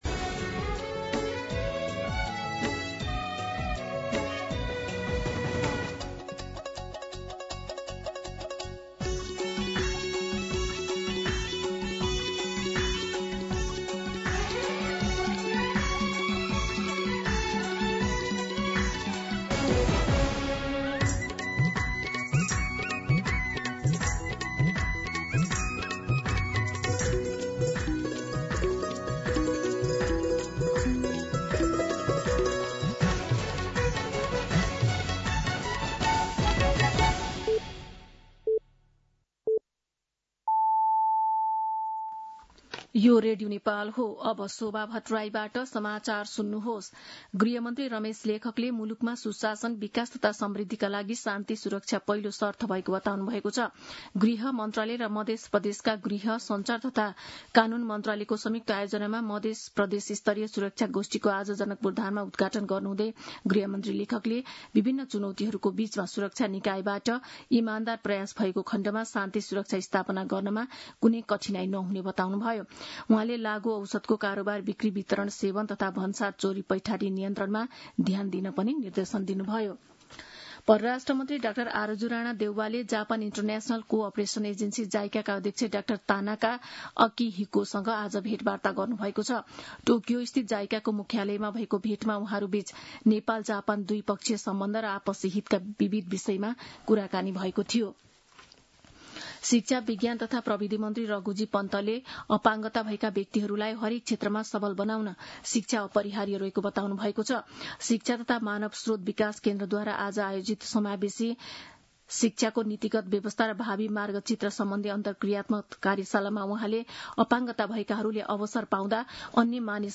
दिउँसो ४ बजेको नेपाली समाचार : ९ जेठ , २०८२